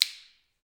Index of /90_sSampleCDs/Roland L-CDX-01/PRC_Clap & Snap/PRC_Snaps